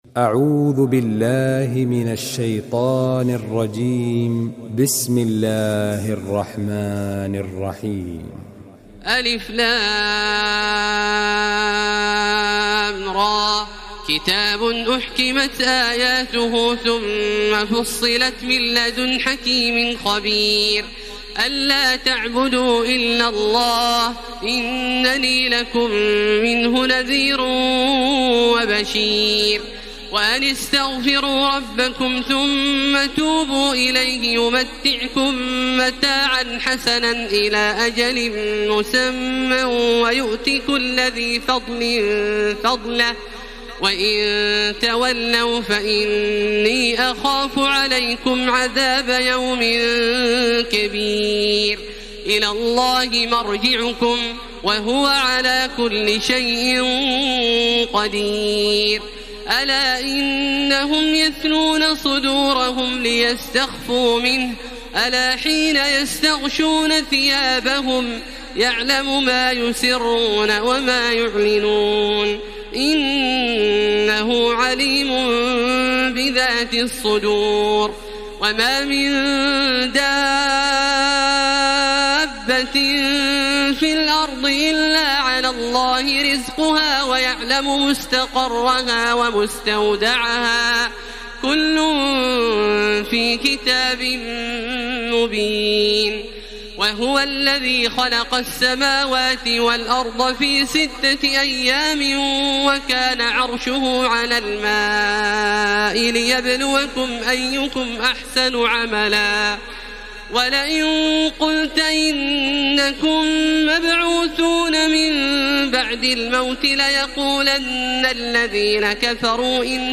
تراويح الليلة الحادية عشر رمضان 1433هـ من سورة هود (1-83) Taraweeh 11 st night Ramadan 1433H from Surah Hud > تراويح الحرم المكي عام 1433 🕋 > التراويح - تلاوات الحرمين